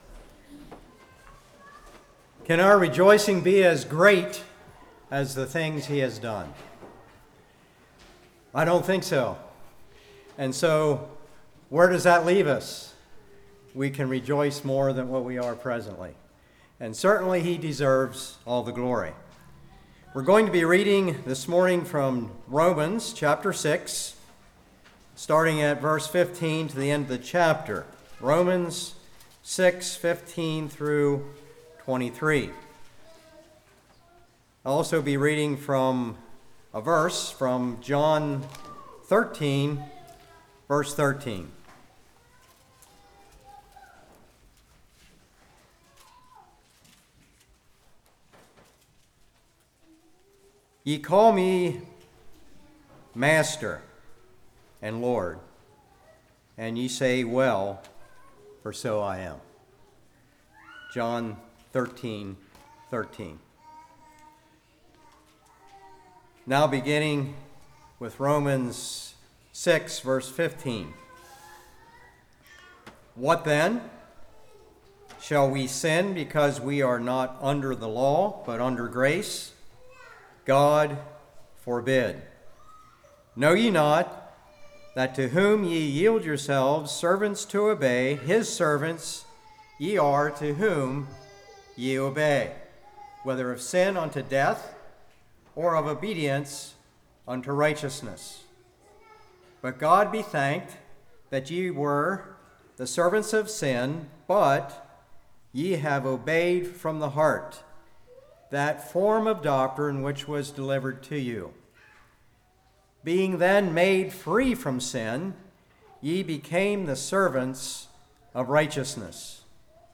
Passage: Romans 6:15-23, John 13:13 Service Type: Morning